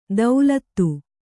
♪ daulattu